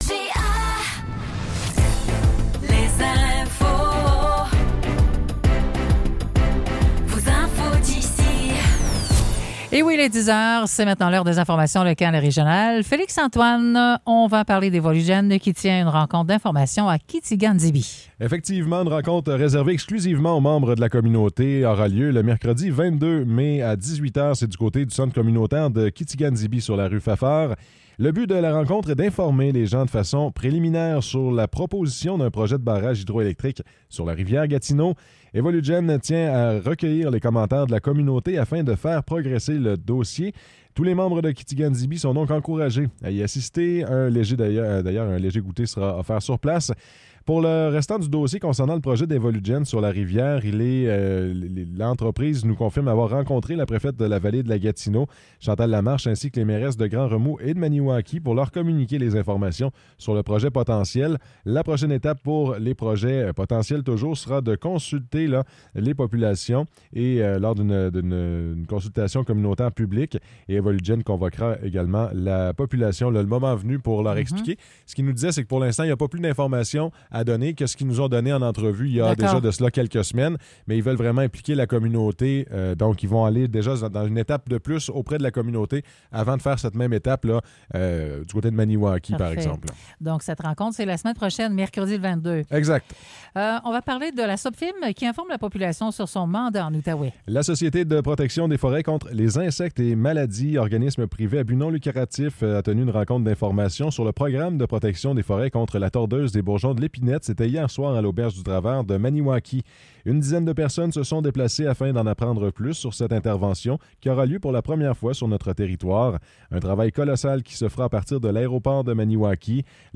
Nouvelles locales - 15 mai 2024 - 10 h